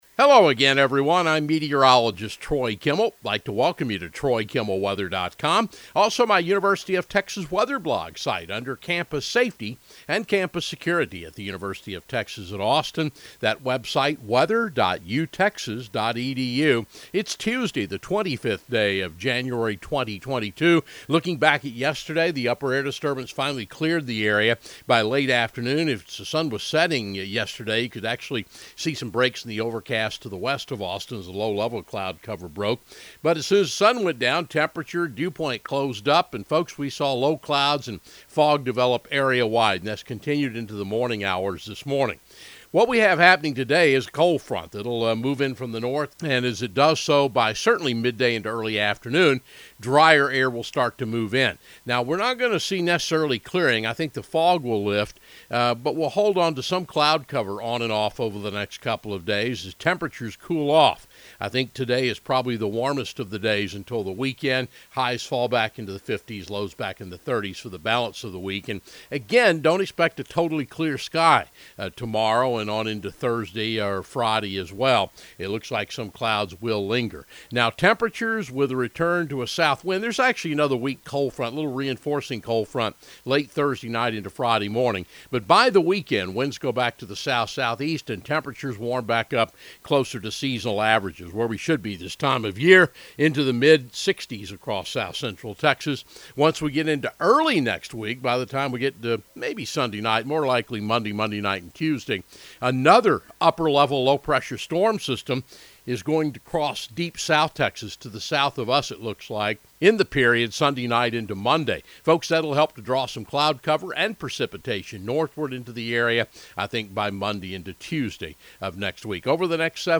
Audio Weather Webcast